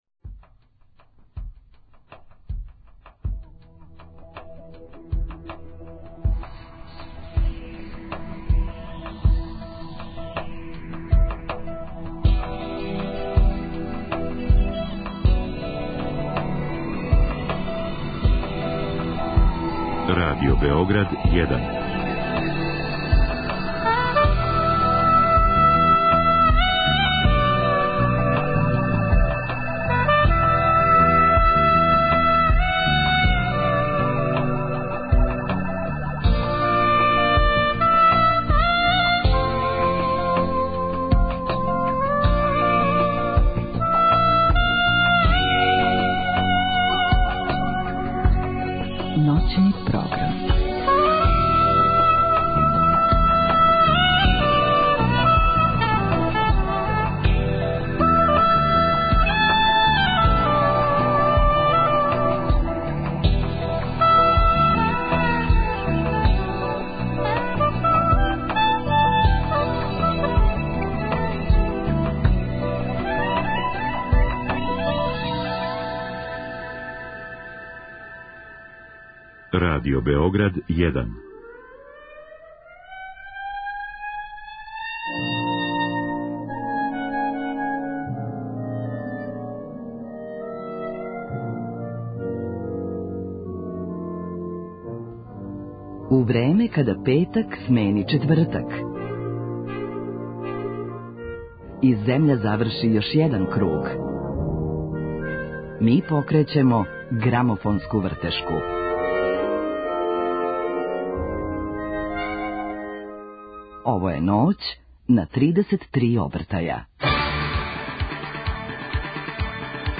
Сваког петог дана у недељи, тачно у поноћ, слушаћемо и заједно откривати годове грамофонских плоча. Слушаћемо музику различитих жанрова и путоваћемо кроз време.